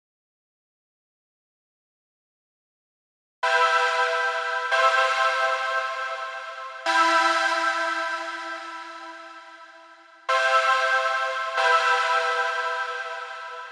来自不列颠哥伦比亚省的海洋之声" 紧缩
描述：脚在沙地上踩碎一只螃蟹
标签： 紧缩 挤压 粉碎
声道立体声